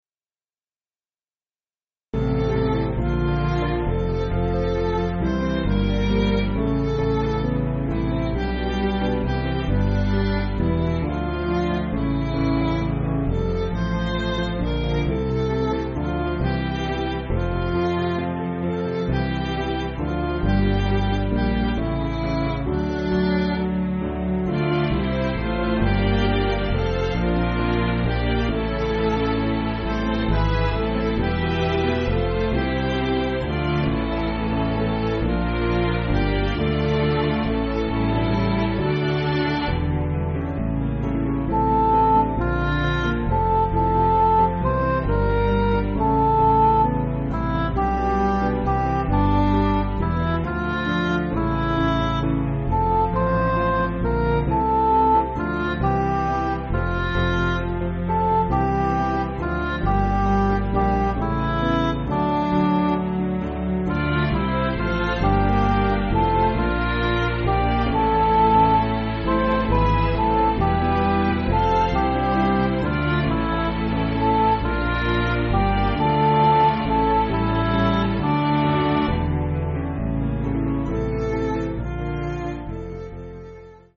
Piano & Instrumental
(CM)   4/Dm